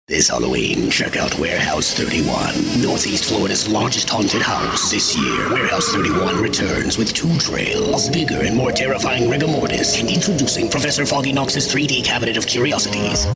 Versatile, animated, conversational, and relatable.